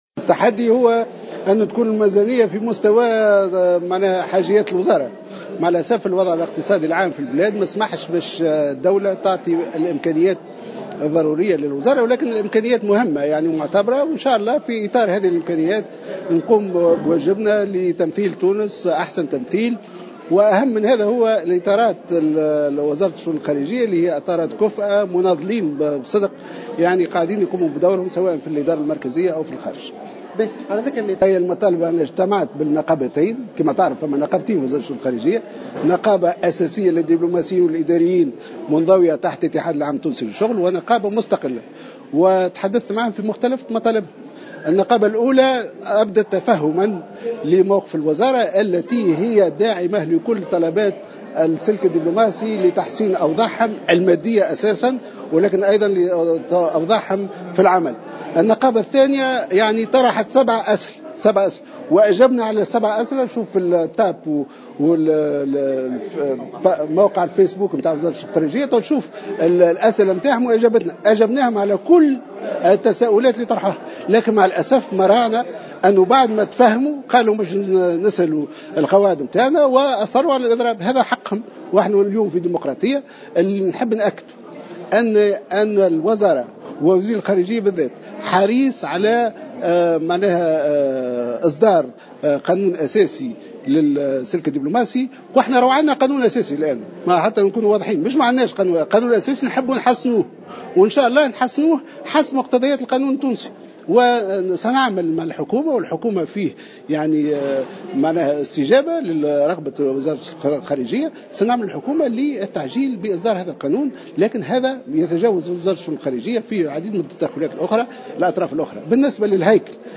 وجاءت هذه التصريحات على اثر جلسة استماع له من قبل لجنة الحقوق و الحريات والعلاقات الخارجية بمجلس نواب الشعب، لمناقشة ميزانية وزارته لسنة2017، واكبها مراسلنا.